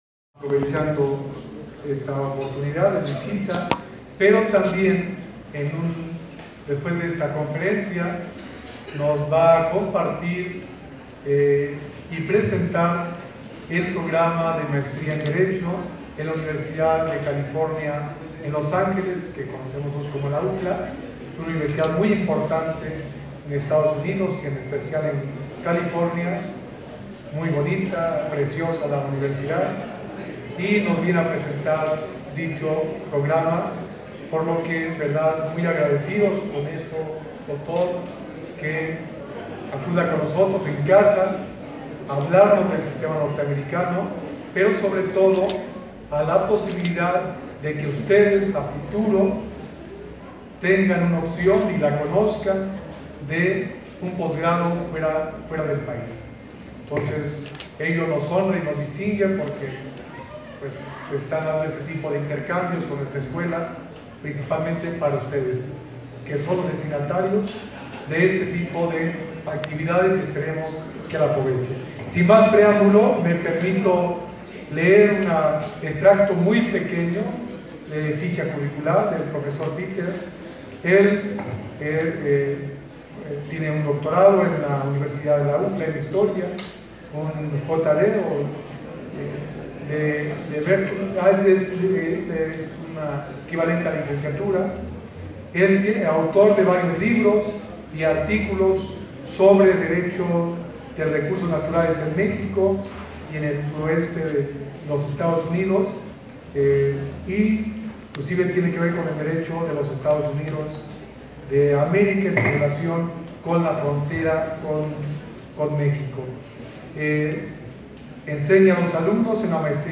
Te compartimos a continuación el PODCAST de la Conferencia Completa: